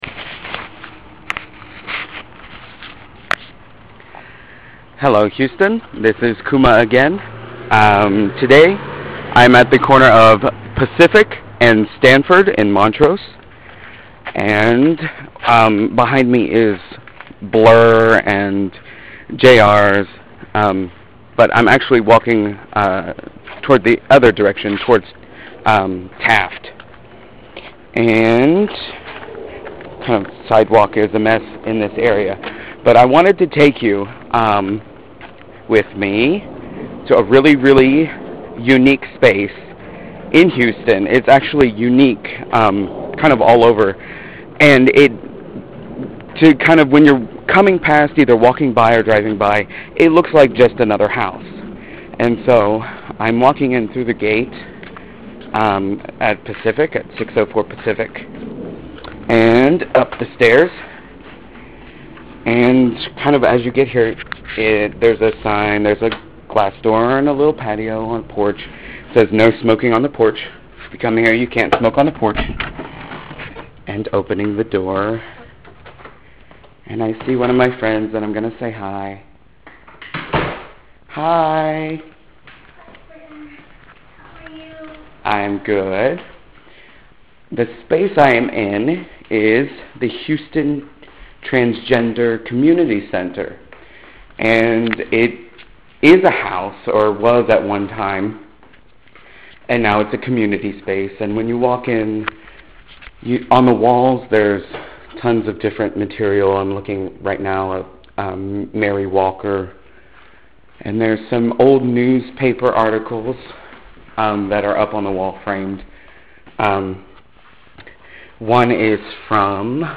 interviews
for an in depth, informational and historic tour of the stories beneath the artifacts at the TG Center Archive.